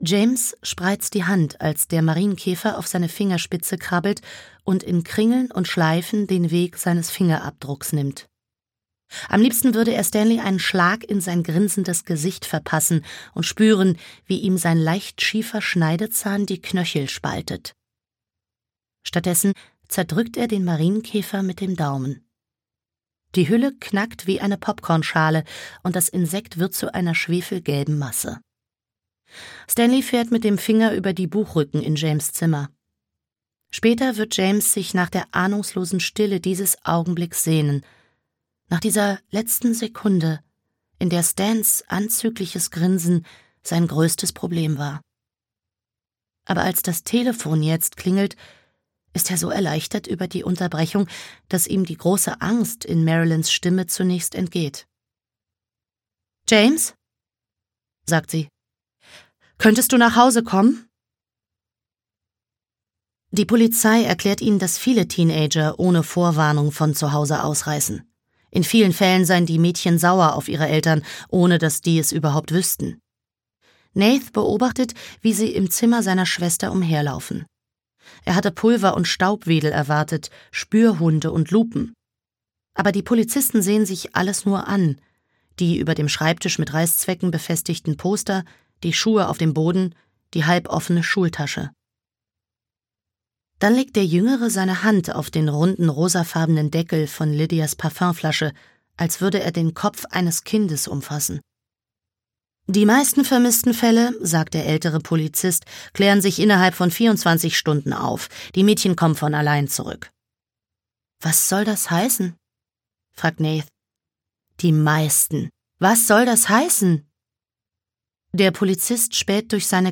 Was ich euch nicht erzählte - Celeste Ng - Hörbuch